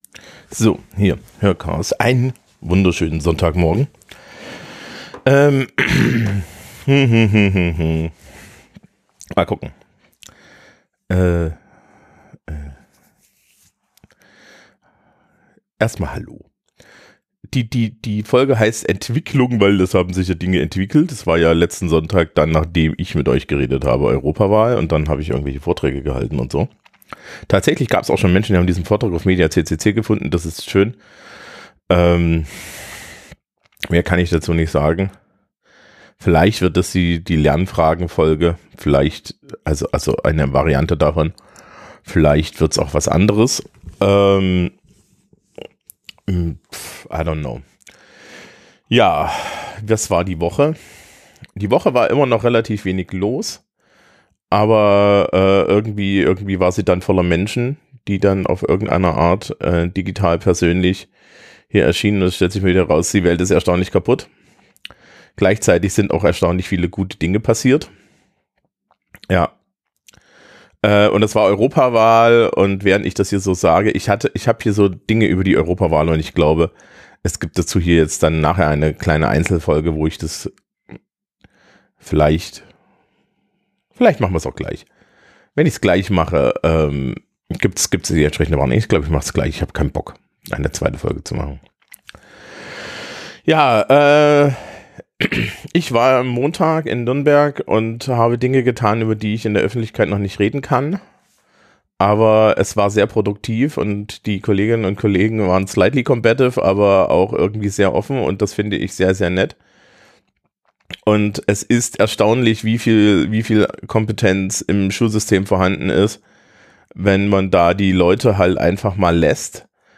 Dies ist eine sehr rantige Folge für die Menschen, die das mögen. Bis Minute 9 ist es eher positiv und über Schule, und ab da geht es um die Europawahl und ich bin einmal unzufrieden mit allem.